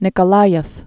(nĭkə-läyəf, nyĭkə-)